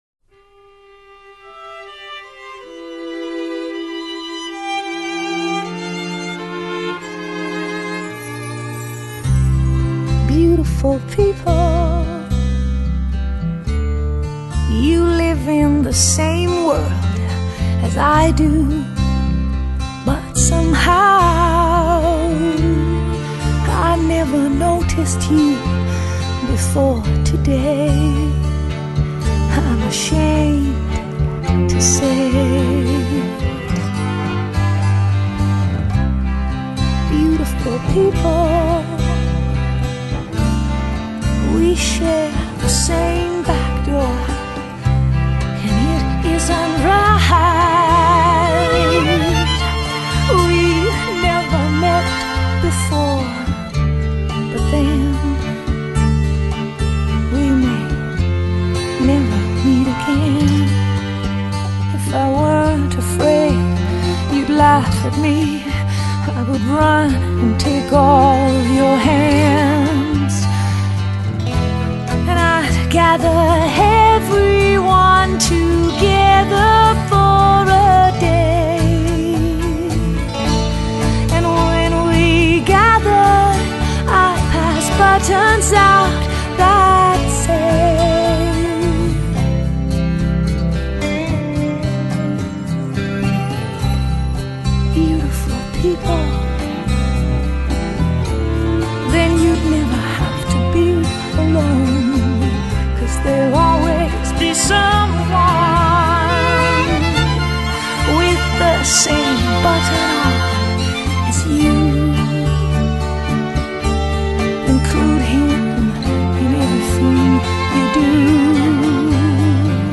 mooi gezongen